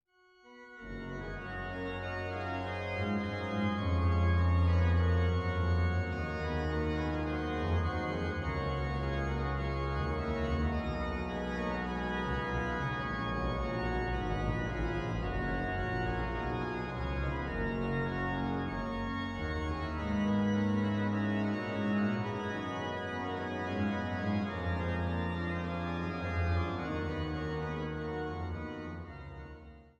Woehl-Orgel in der Thomaskirche zu Leipzig